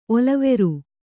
♪ olaveru